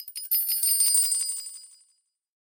Звуки треугольника
Тихонечко трясем звонкий треугольник